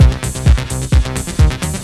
TECHNO125BPM 23.wav